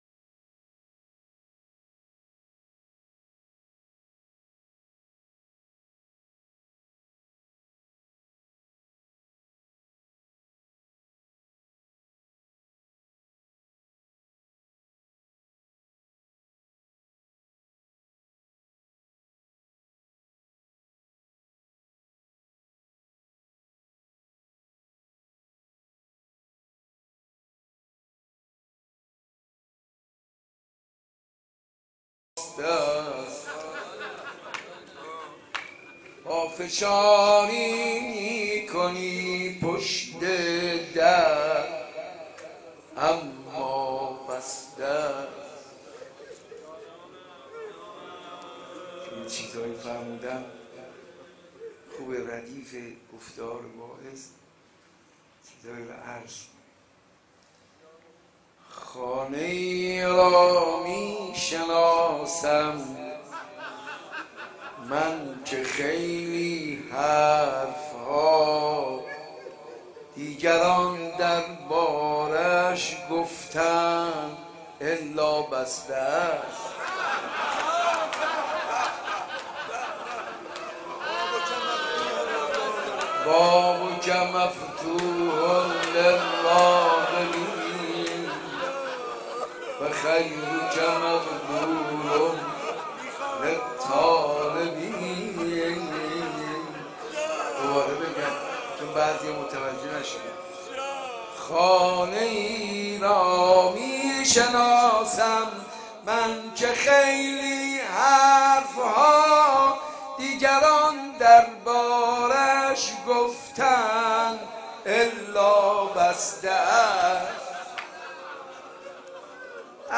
روضه خوانی
در حسینیه موسی بن جعفر برگزار شد